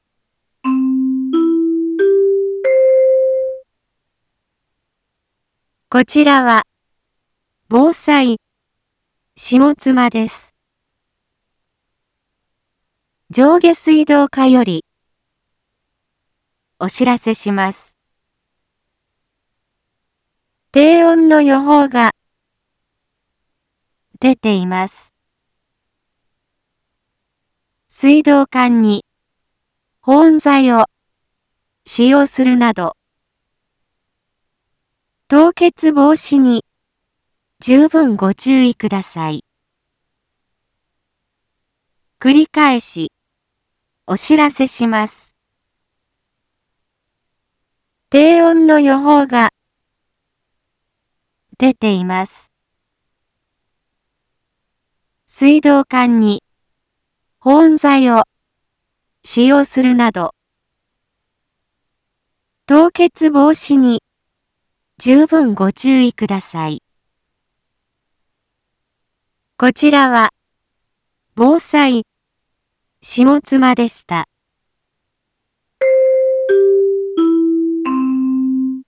Back Home 一般放送情報 音声放送 再生 一般放送情報 登録日時：2022-02-04 16:01:23 タイトル：凍結防止対策のお願い インフォメーション：こちらは、防災下妻です。